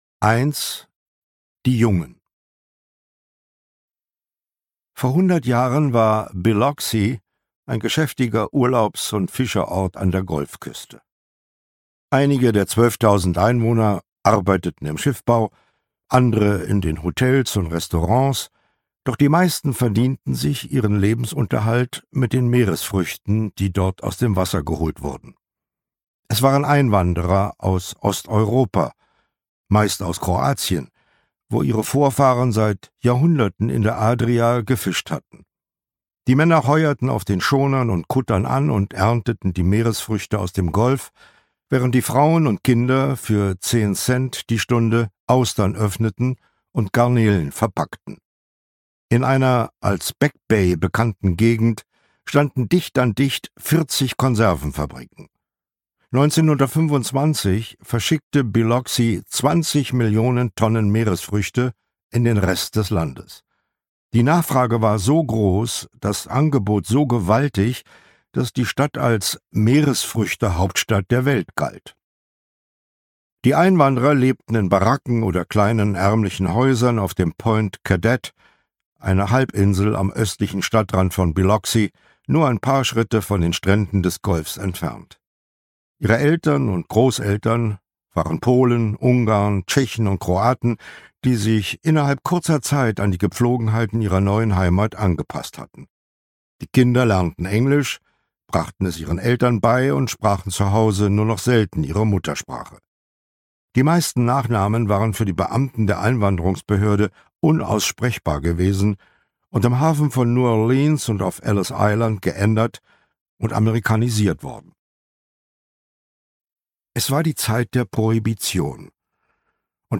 Audio kniha
Ukázka z knihy
• InterpretCharles Brauer